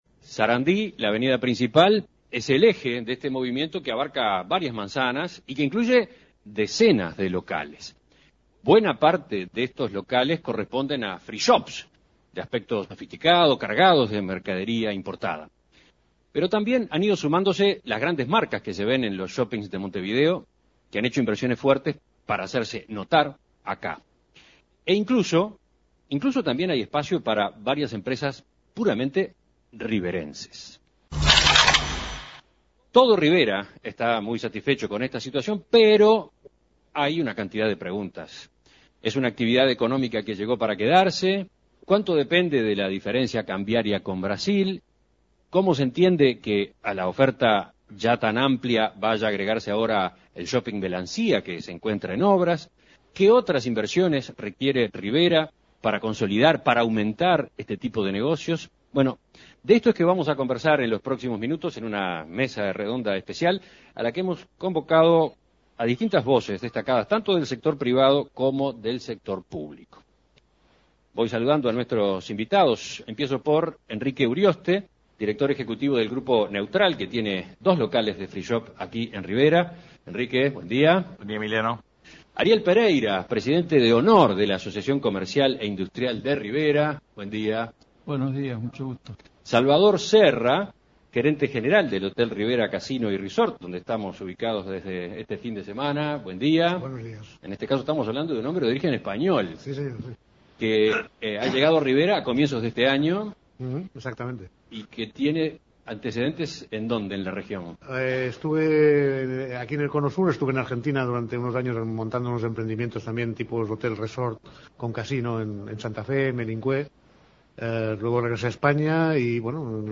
Mesa redonda: desafíos y perspectivas del "turismo de compras" en Rivera (audio)
Entrevistas Mesa redonda: desafíos y perspectivas del "turismo de compras" en Rivera (audio) Imprimir A- A A+ Hace por lo menos 10 años que Rivera vive un verdadero boom del turismo de compras.